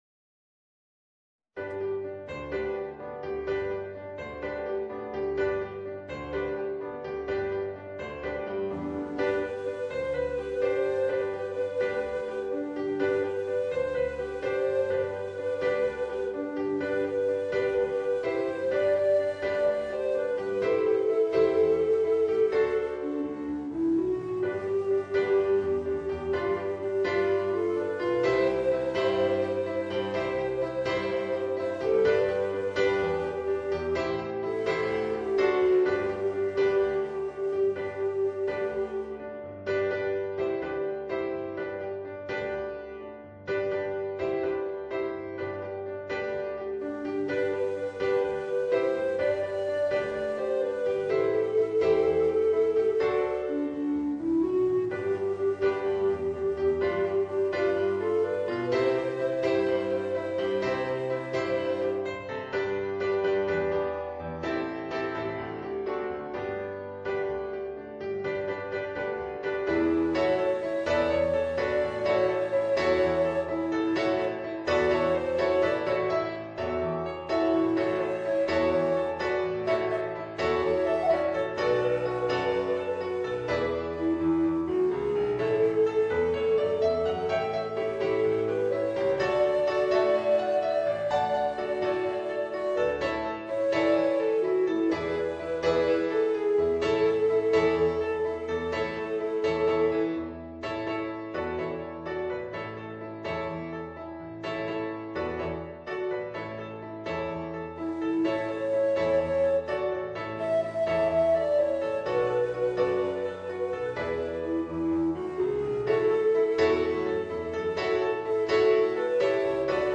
Voicing: Tenor Recorder and Piano